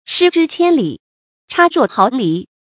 shī zhī qiān lǐ，chà ruò háo lí
失之千里，差若毫厘发音